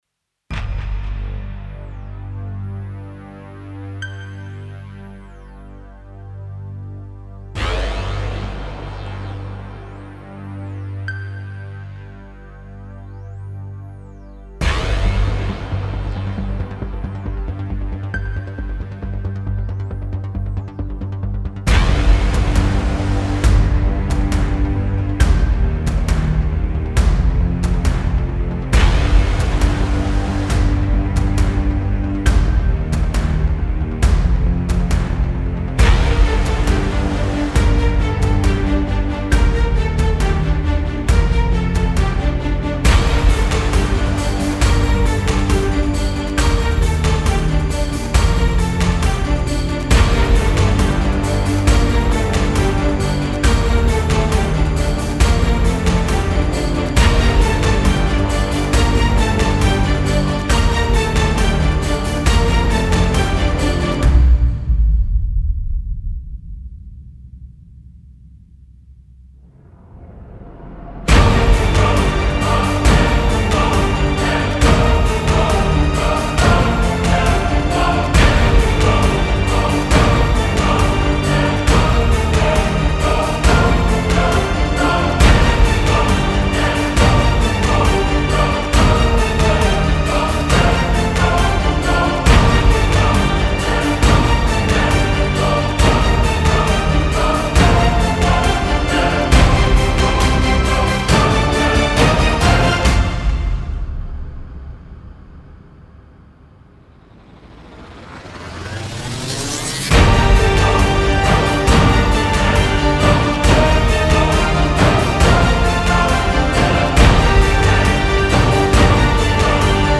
Hilfe beim Mastering (Hobby Musik, Epic Hybrid Trailer)
Nun habe ich einen "Epic Hybrid Trailer"-Track gemacht und festgestellt, dass Referenz-Stücke viel mehr knallen...
Ich habe vor dem Inflator etwas OTT hinzugefügt und dadurch (für meine Ohren) ein ähnliches Ergebnis erzielt.